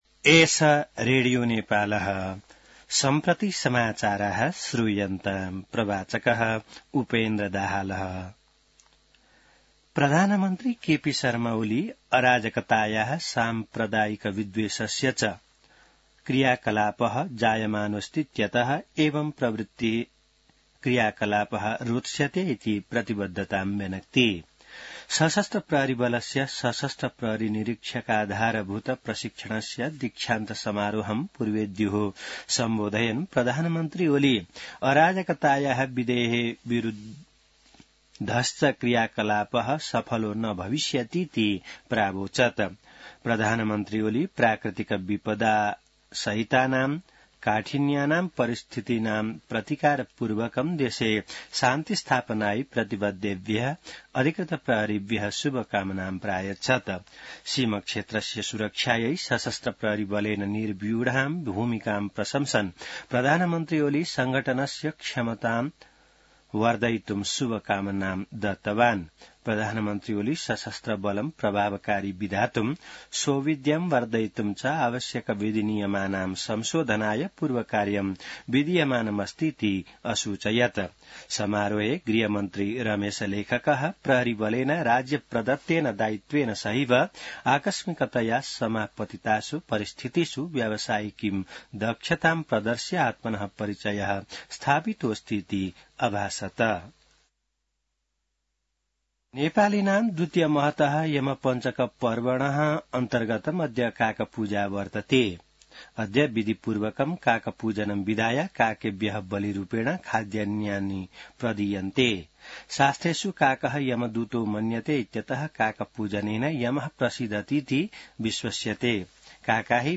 An online outlet of Nepal's national radio broadcaster
संस्कृत समाचार : १५ कार्तिक , २०८१